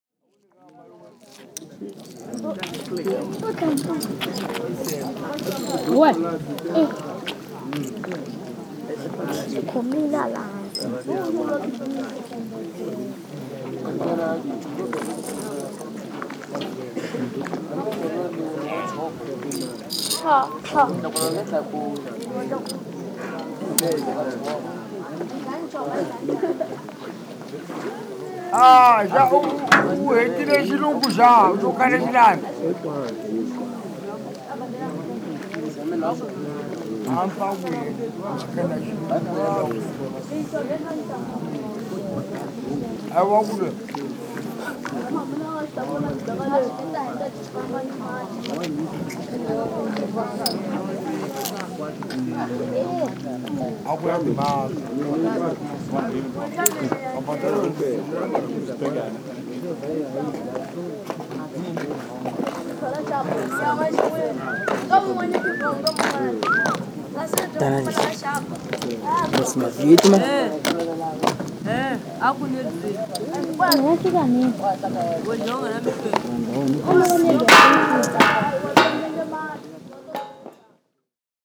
Marracuene – En attendant le bac – pcm-d50 – stéréo AB